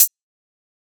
kits/OZ/Closed Hats/Hihat (WhitePeople).wav at main
Hihat (WhitePeople).wav